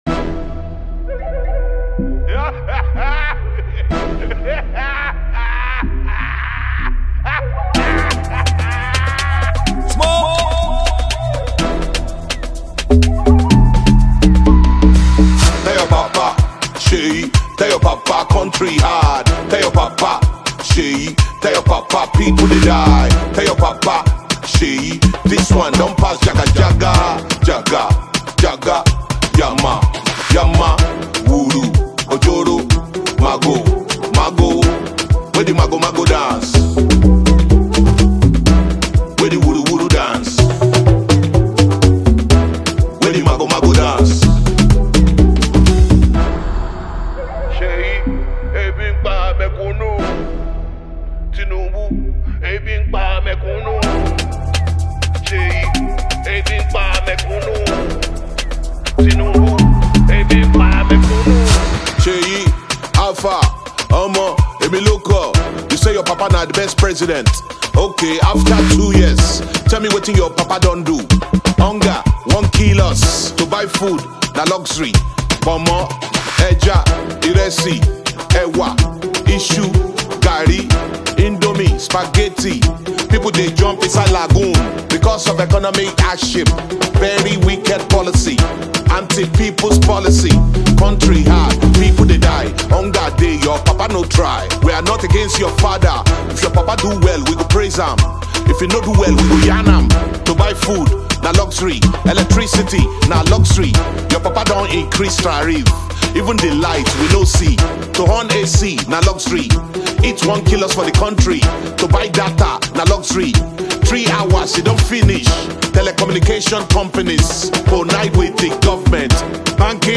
A veteran rapper and outspoken social activist
Afrobeats